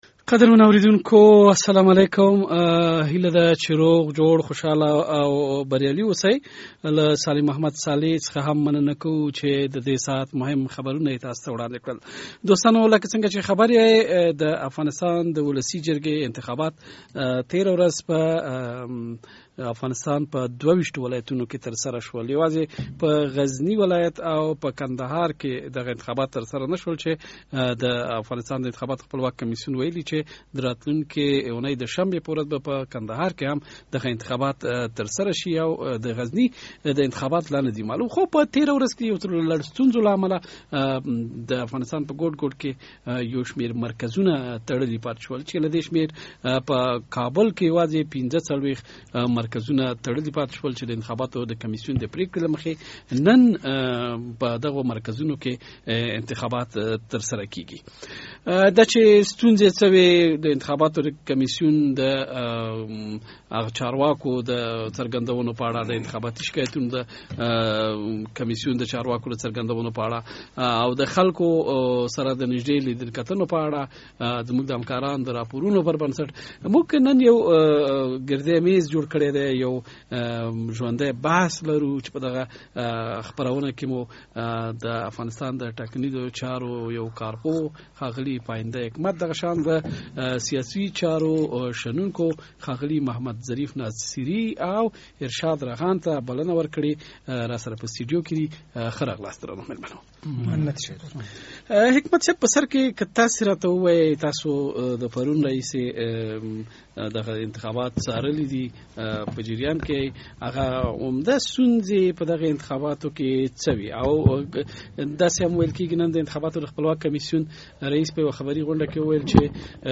انتخابات د ځوانانو له انده، د افغانستان د ولسي جرګې د انتخاباتو په اړه بحث، میلمانه د ټاکنیزو چارو کارپوه ...